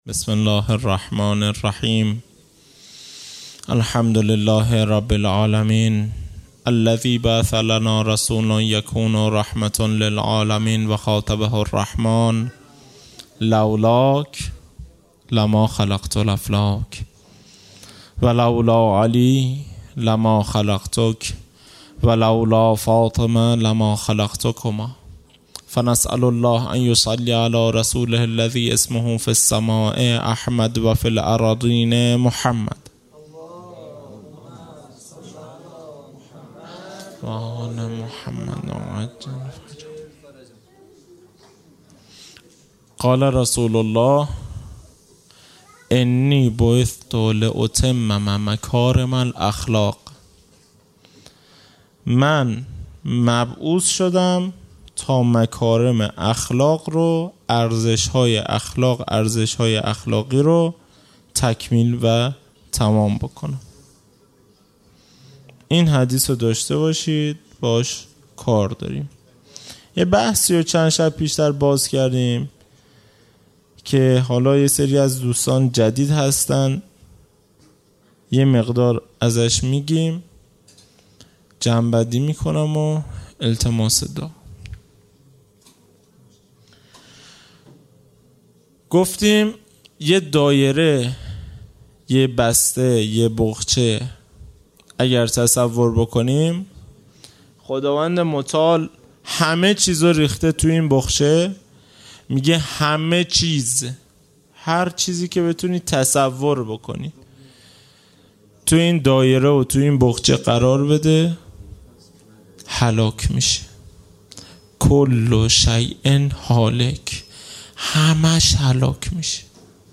خیمه گاه - هیئت بچه های فاطمه (س) - سخنرانی
جلسۀ هفتگی | شهادت حضرت رقیه(س)